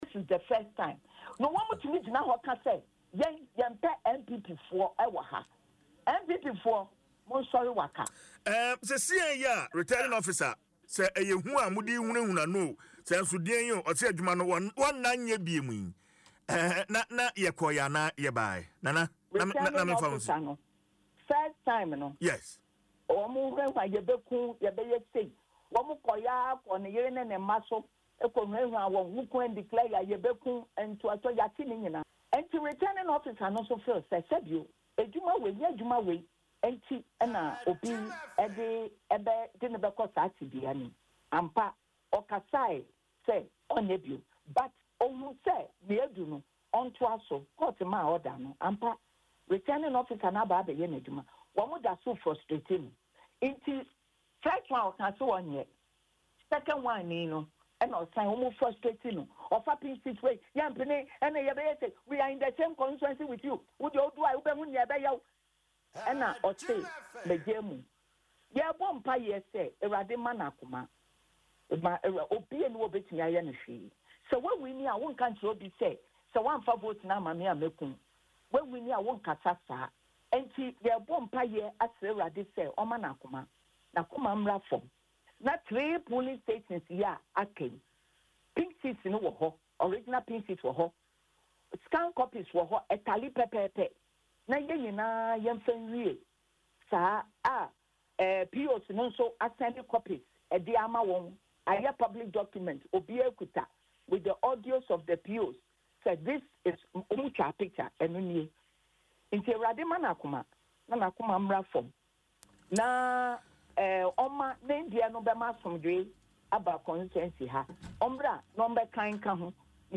But speaking on Adom FM’s morning show Dwaso Nsem